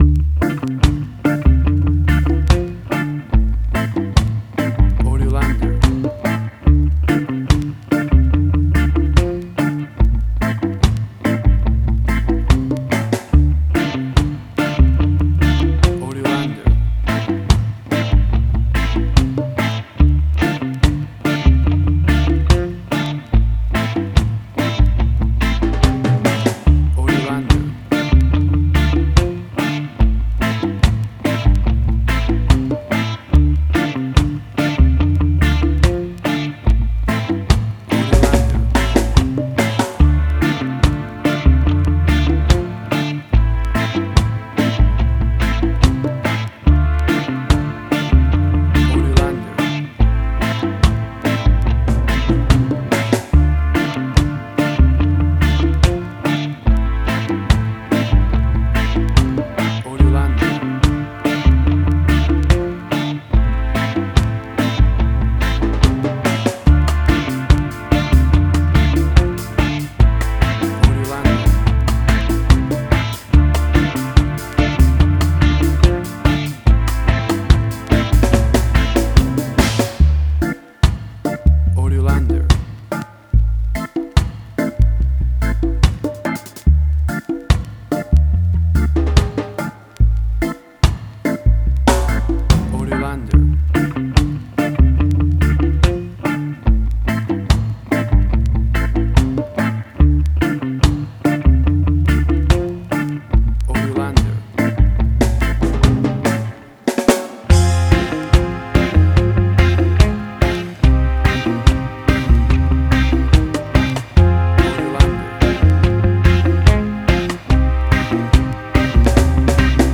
Reggae caribbean Dub Roots
Tempo (BPM): 72